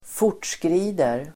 Uttal: [²f'or_t:skri:der]